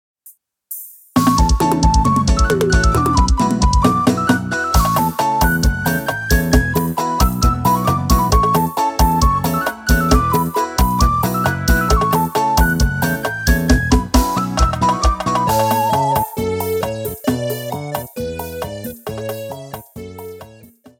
• Demonstrativo Pagodão: